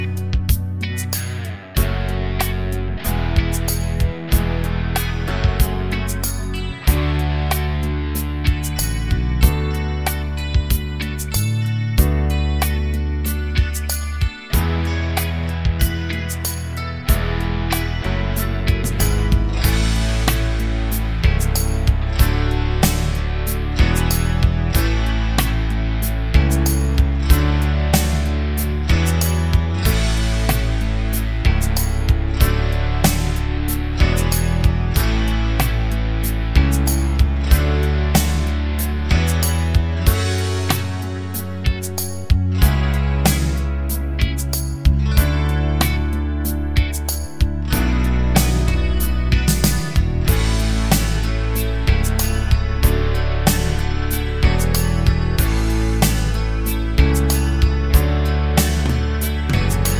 no Backing Vocals With Count Soft Rock 4:22 Buy £1.50